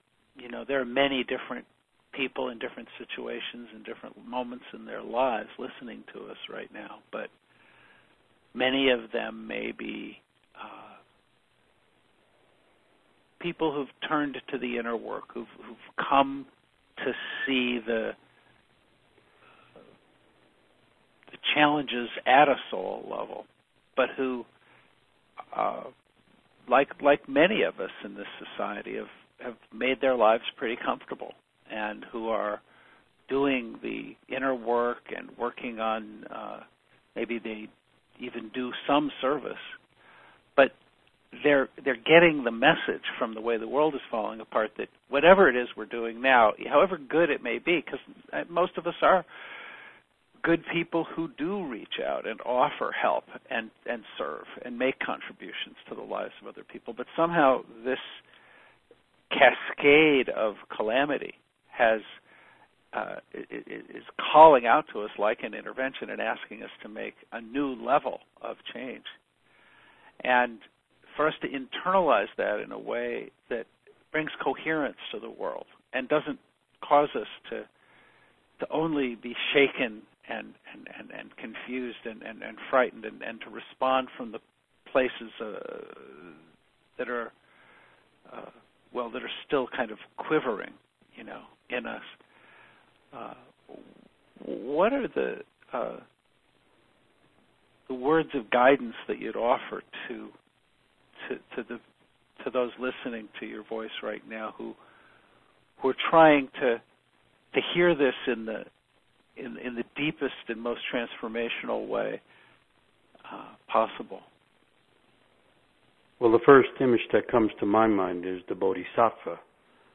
From a public conversation with storyteller and mythic thinker